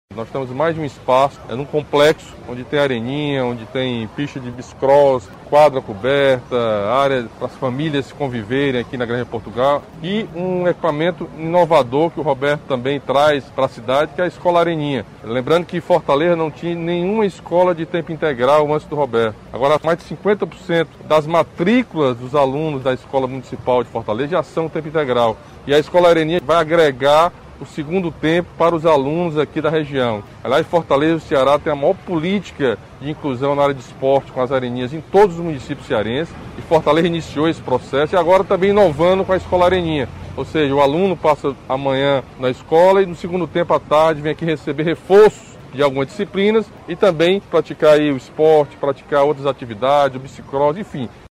O governador Camilo Santana falou sobre a estrutura local e destacou a evolução da educação na Capital, onde mencionou o novo projeto educacional que se alia às areninhas, em Fortaleza.